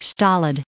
685_stolid.ogg